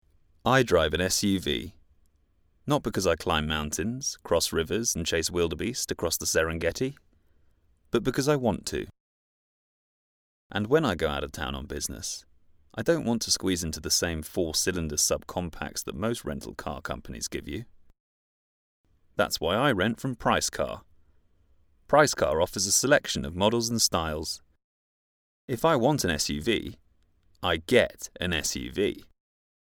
His voice is naturally conversational but also has an authoritative tone to it.